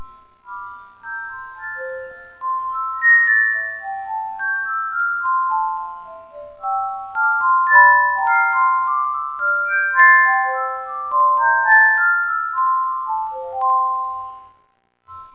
ここにまとめてオルゴールを録音してWAVEファイルにしてみました。
いまいちの音質です。
オルゴール]